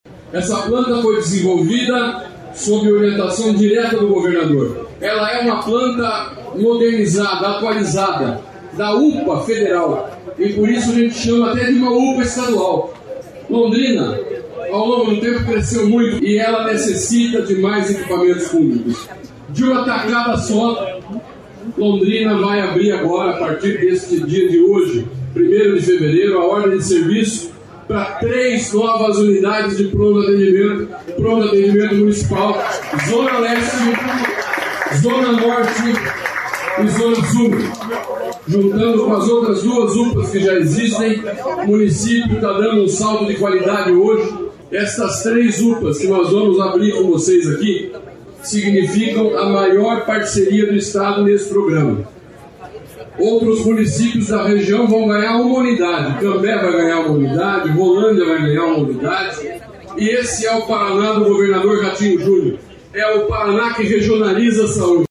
Sonora do secretário da Saúde, Beto Preto, sobre o anúncio de três novos Pronto Atendimento Municipais em Londrina | Governo do Estado do Paraná